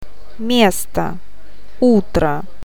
O ääntyy painottomana a :n kaltaisena, e ja я i :n tai ji :n tapaisena äänteenä.